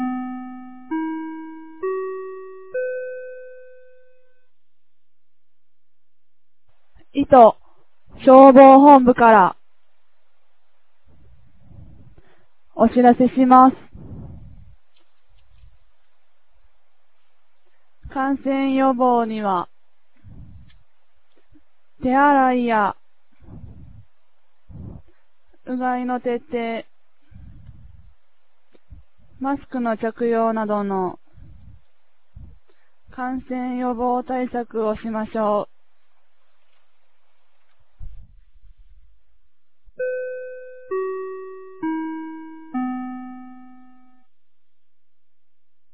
2024年12月09日 10時00分に、九度山町より全地区へ放送がありました。